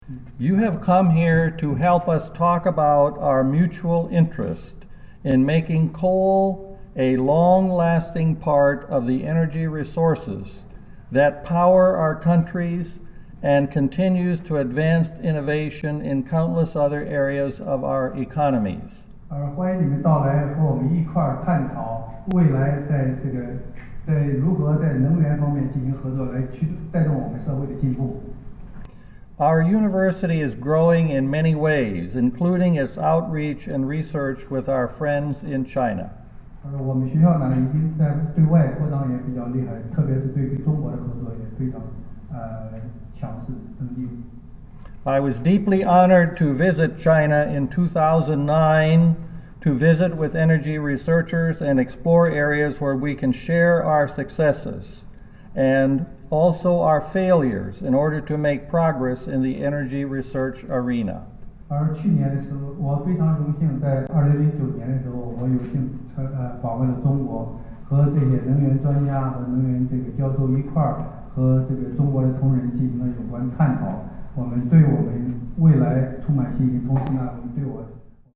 discusses the importance of the relationship between WVU and CUMT followed by a translation of his words to Chinese.